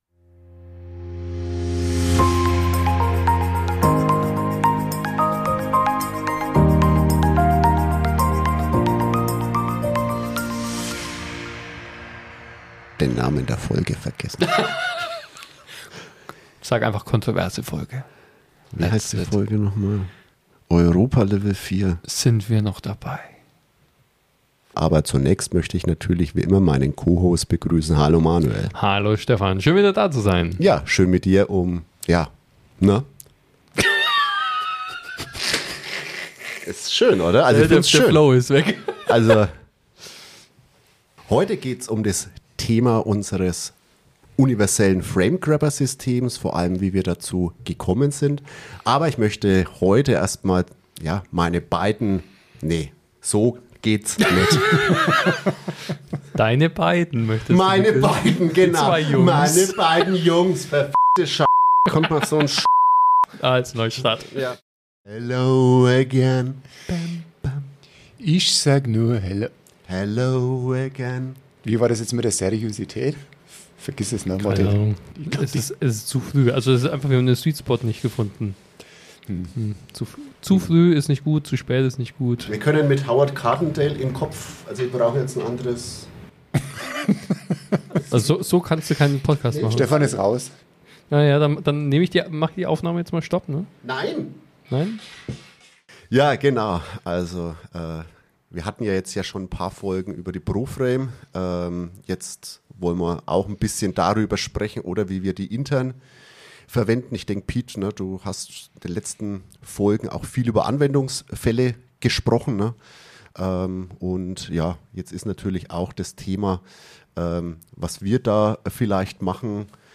Beschreibung vor 3 Monaten Versprecher, Denkpausen und jede Menge Unsinn – wir haben die lustigsten Momente aus den Aufnahmen unseres Podcasts gesammelt.